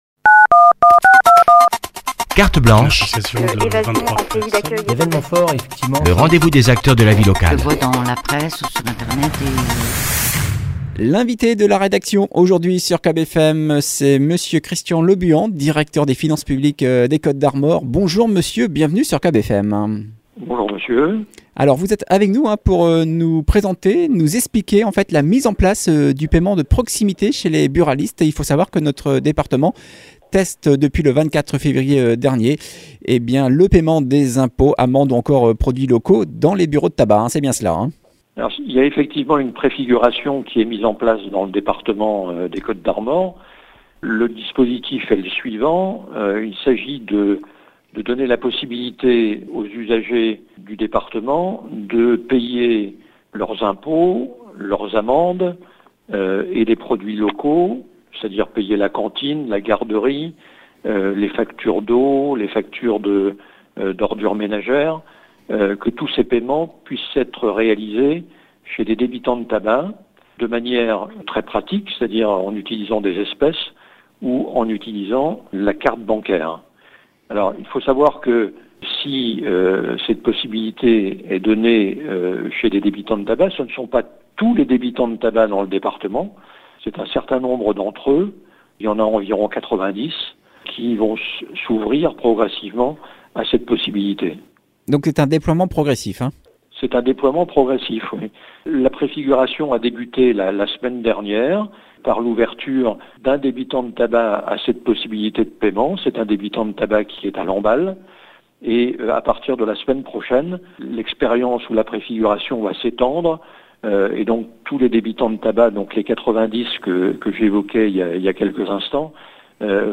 Invité de la rédaction aujourd’hui, Christian Le Buhan, directeur de Finances publiques des Côtes d’Armor, explique ce dispositif qui devrait être généralisé au début de l’été sur le plan national